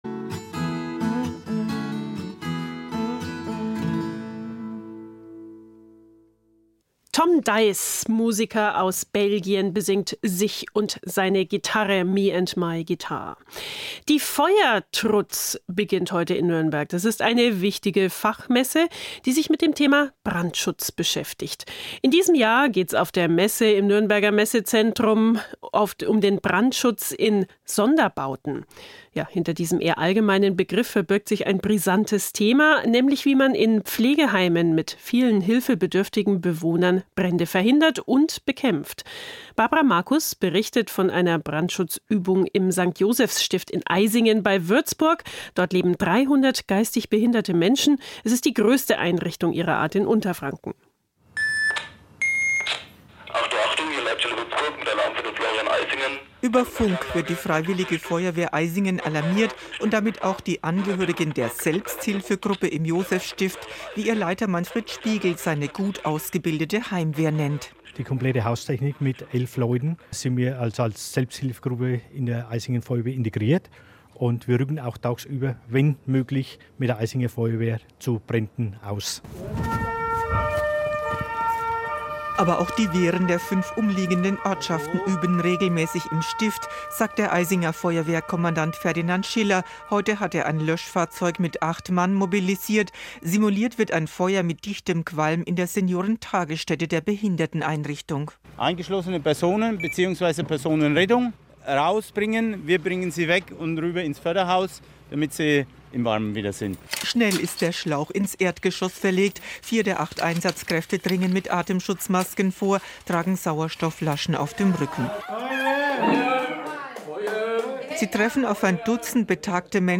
Den Mitschnitt aus dem Programm des Bayerischen Rundfunks können Sie sich